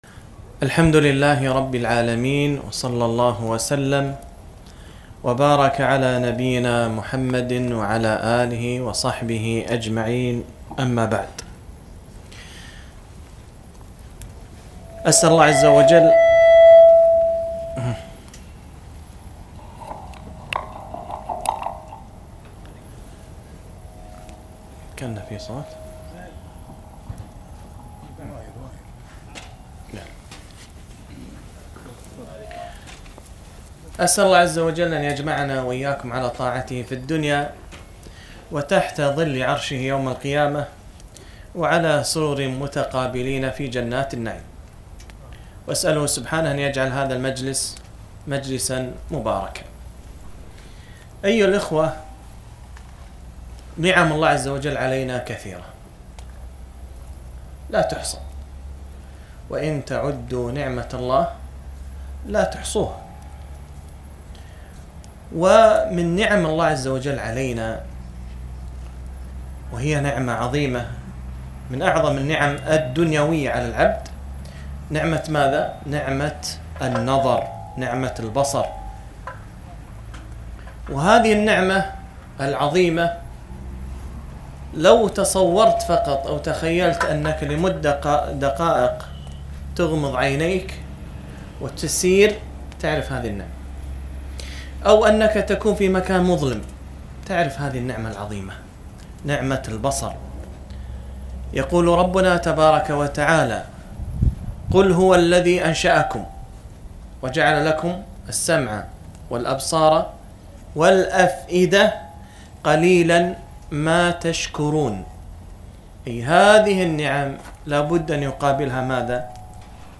محاضرة - ( قل للمؤمنين يغضوا من أبصارهم ويحفظوا فروجهم)